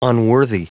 Prononciation du mot unworthy en anglais (fichier audio)
Prononciation du mot : unworthy